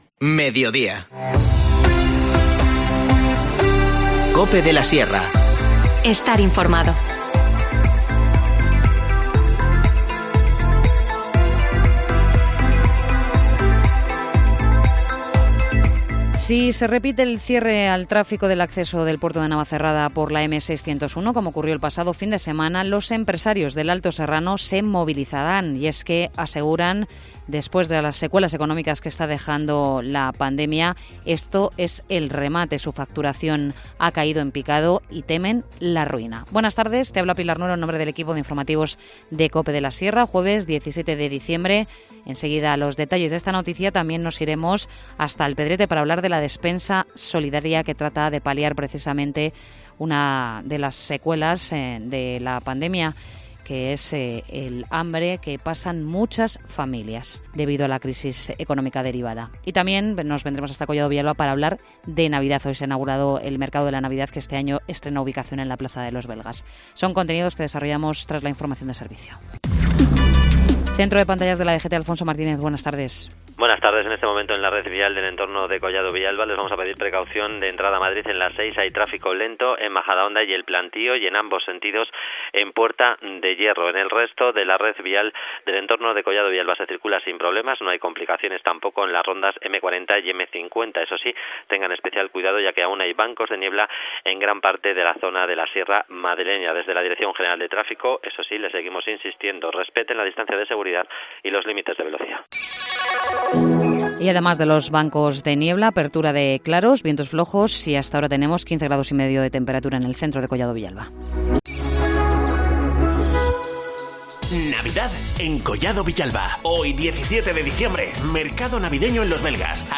Informativo Mediodía 17 diciembre